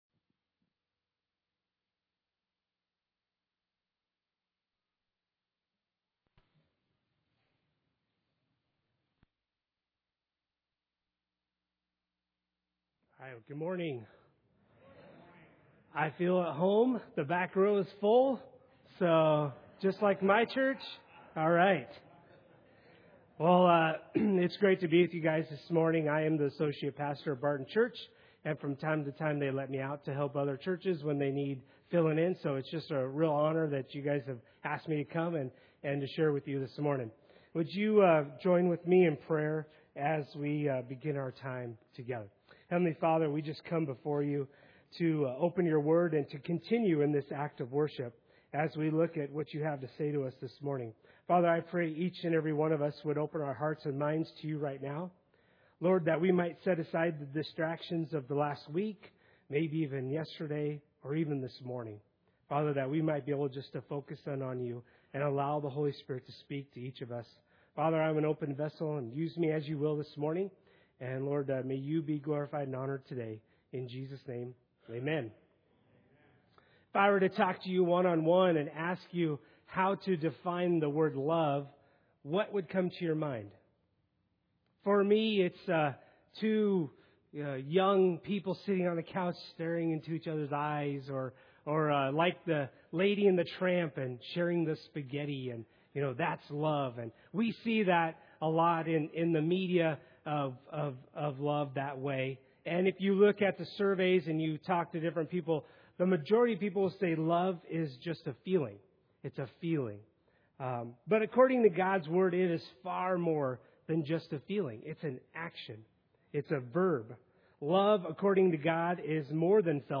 Passage: Romans 12 Service Type: Sunday Service